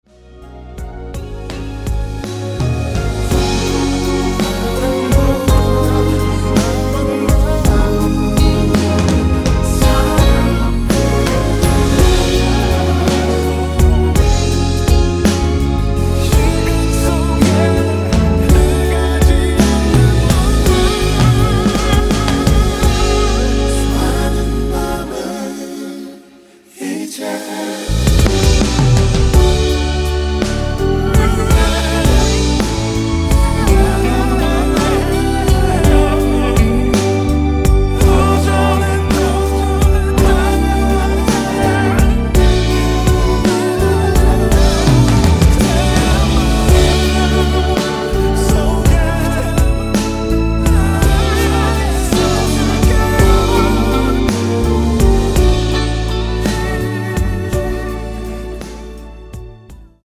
원키에서(-1)내린 코러스 포함된 MR입니다.(미리듣기 참조)
Db
앞부분30초, 뒷부분30초씩 편집해서 올려 드리고 있습니다.
중간에 음이 끈어지고 다시 나오는 이유는